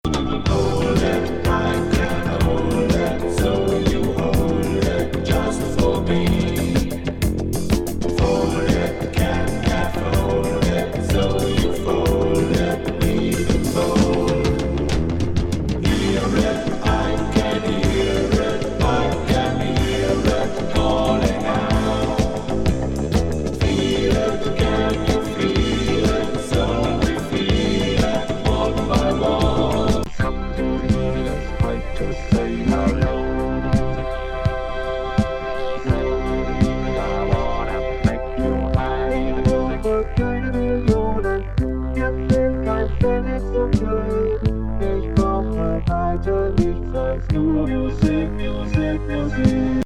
シンセ＆ボコーダー・ボーカルの宇宙人
プログレ・ディスコ